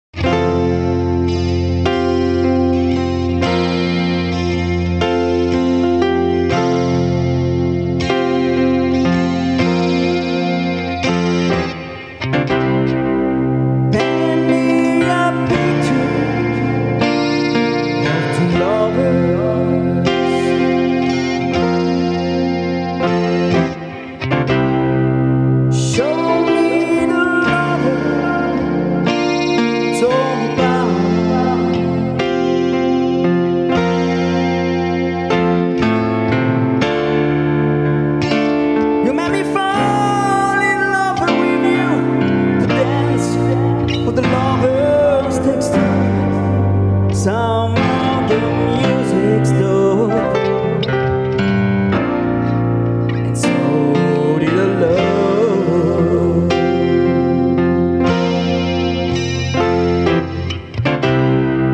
Acoustic Set